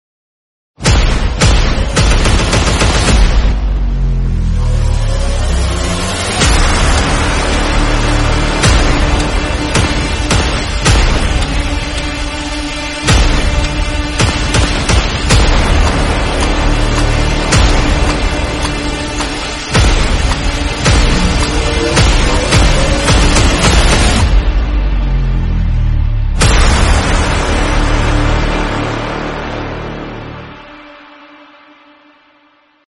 Segway Super Villain 6speed 4cyl sound effects free download
Segway Super Villain 6speed 4cyl turbo paddle shift.